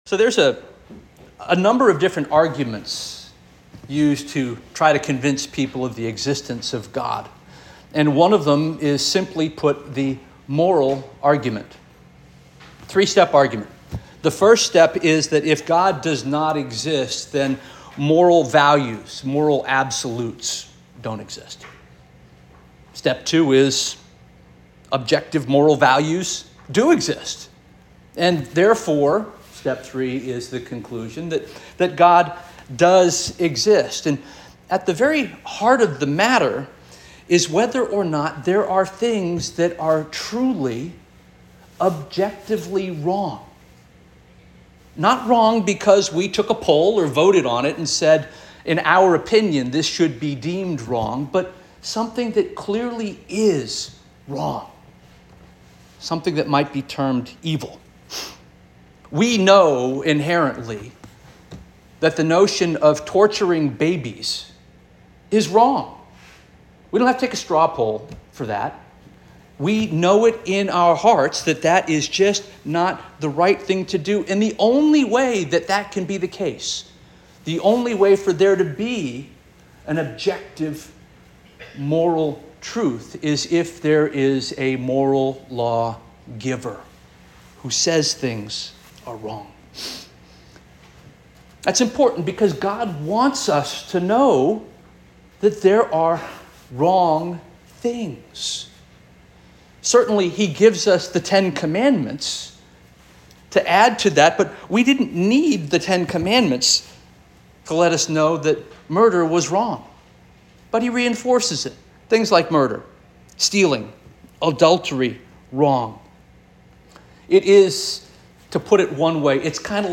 July 14 2024 Sermon - First Union African Baptist Church